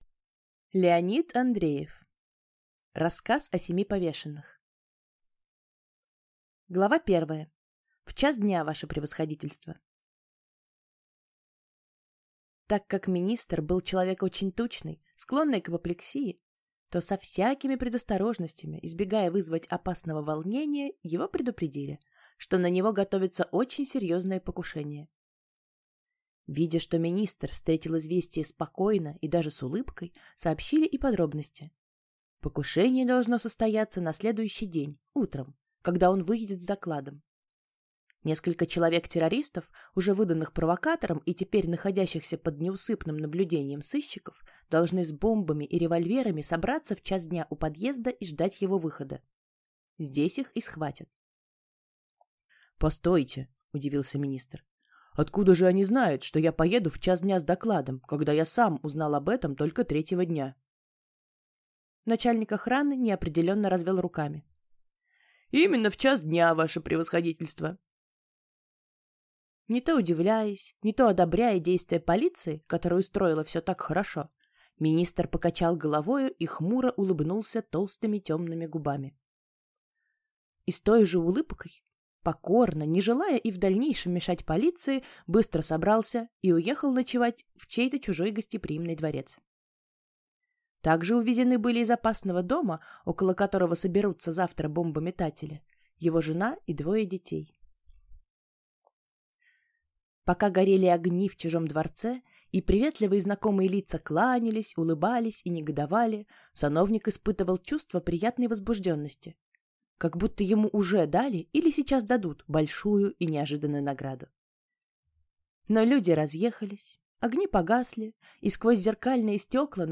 Аудиокнига Рассказ о семи повешенных | Библиотека аудиокниг